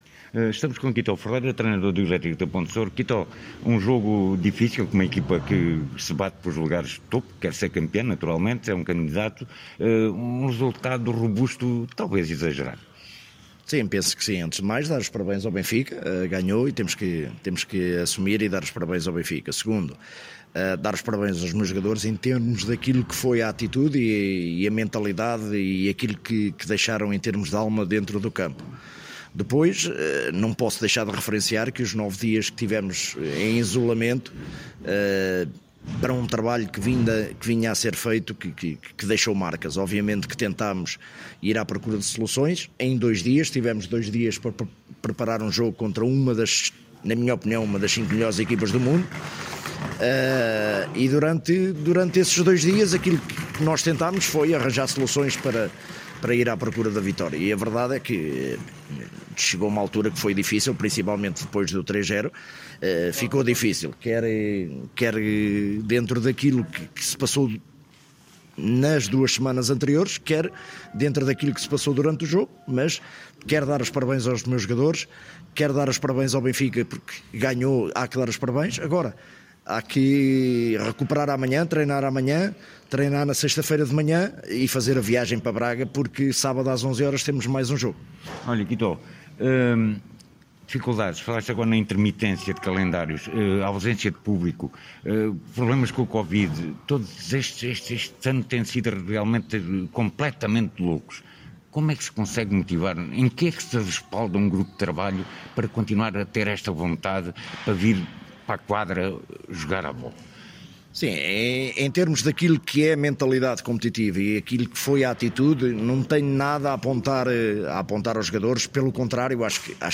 No final fomos falar com os treinadores: